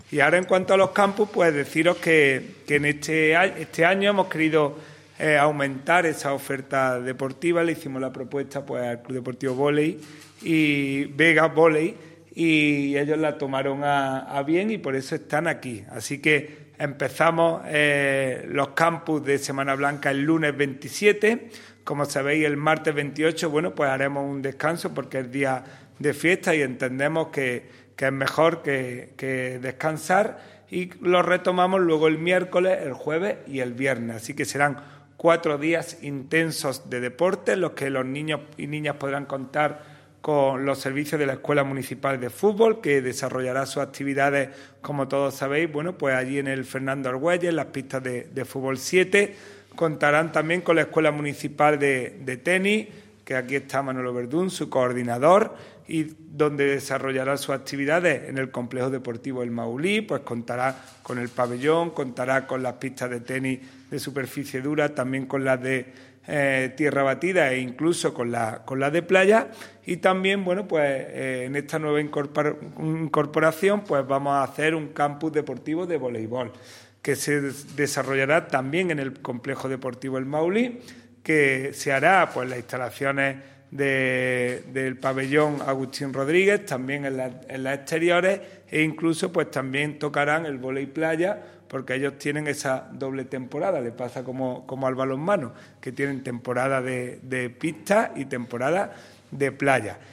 El teniente de alcalde delegado de Deportes, Juan Rosas, ha presentado hoy en rueda de prensa una nueva iniciativa del Área de Deportes a desarrollar con motivo de los días escolares no lectivos que conlleva el tradicional desarrollo en nuestra provincia de las denominadas como vacaciones de Semana Blanca.
Cortes de voz